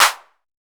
DB - Claps & Snares (36).wav